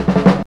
JAZZ FILL 4.wav